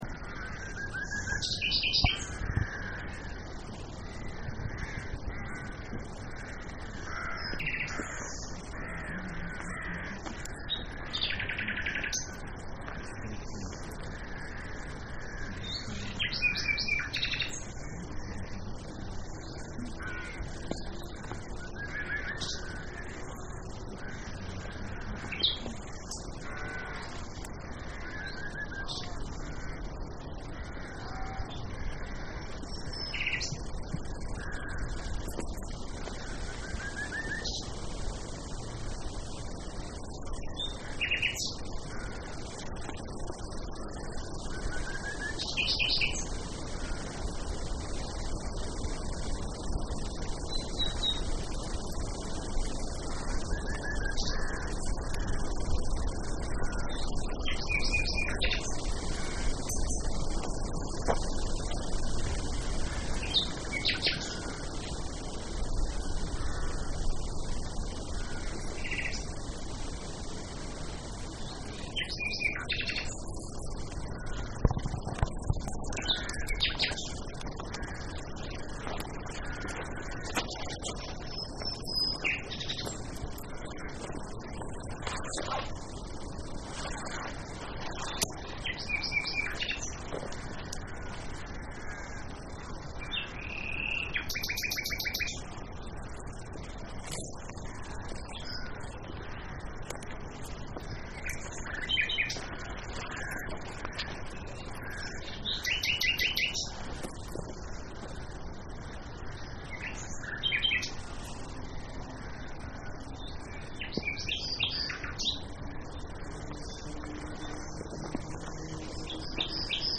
We went on a field trip organised by South Somerset RSPB group to West Sedgmoor reserve.
It was a bit late in the season and not the best I've heard.
Nightingale_STE-008.mp3